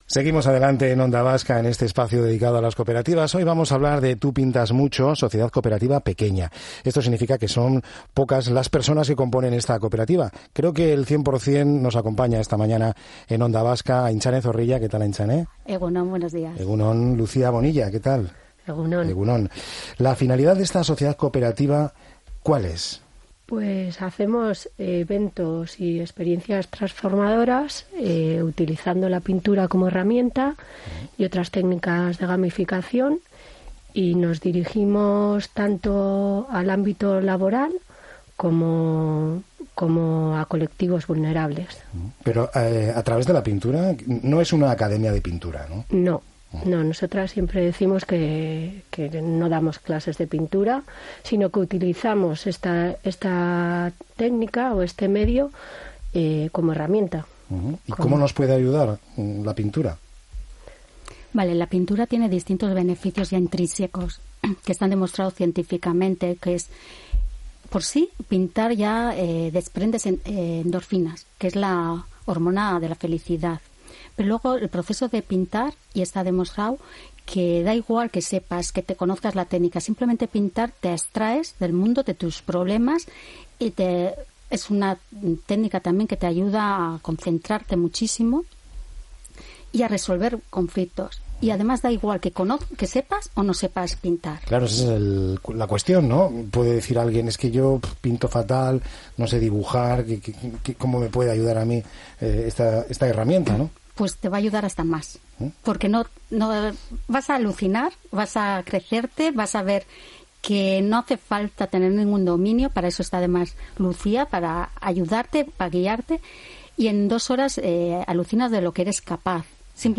Historias de calle, testimonios cómplices y una audiencia que se siente protagonista. Voces cercanas que conectan de buena mañana con lo que está pasando a nuestro alrededor.